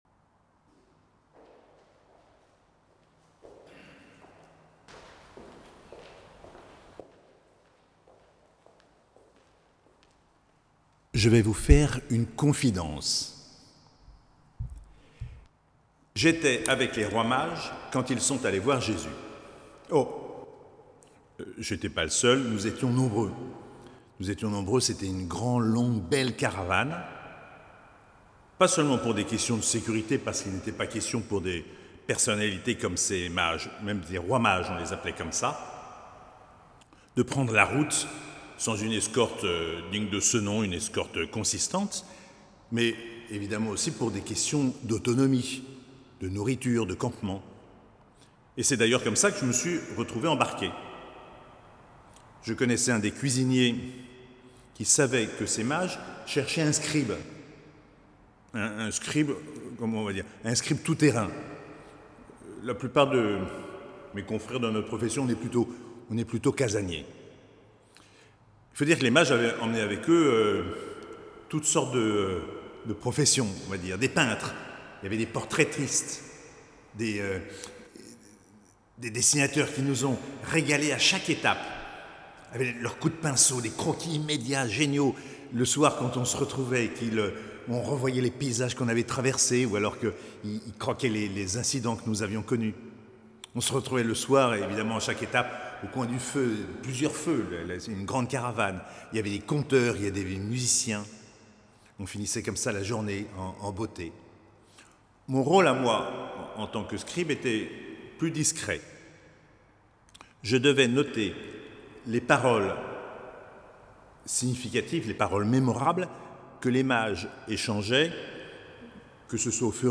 Dimanche de l'Epiphanie - 2 janvier 2022